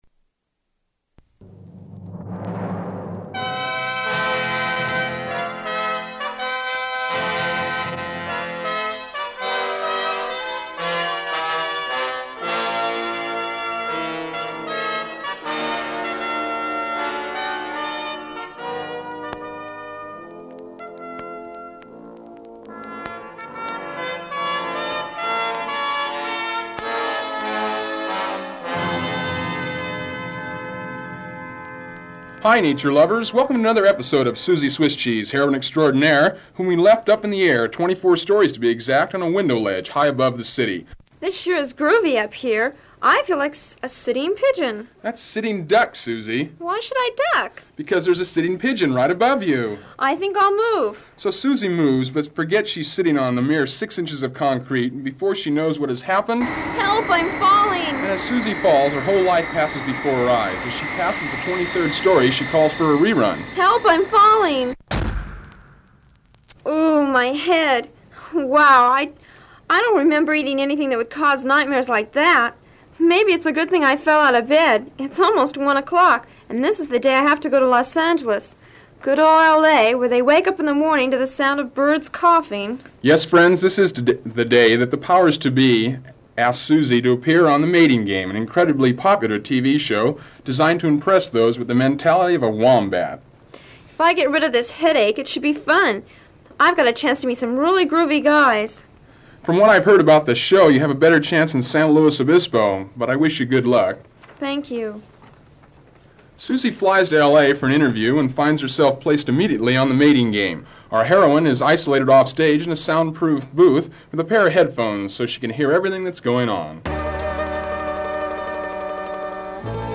Christmas carol medley outro
Open reel audiotape